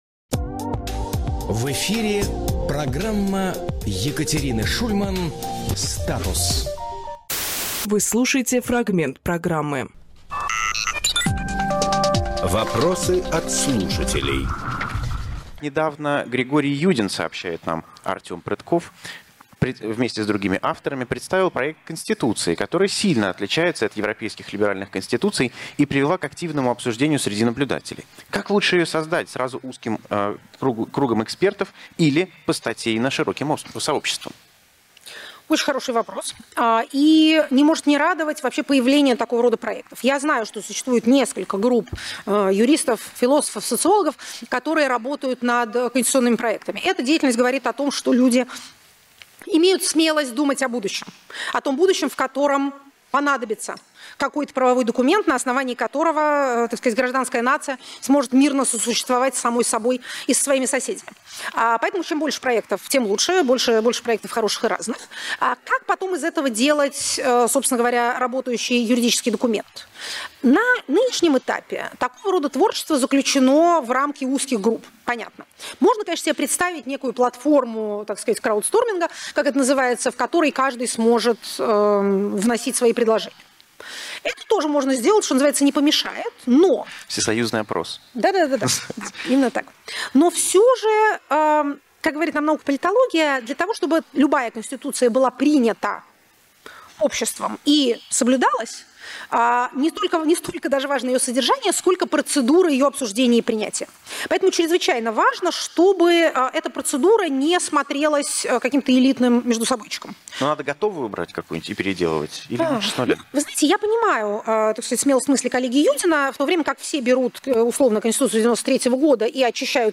Фрагмент эфира от 25.06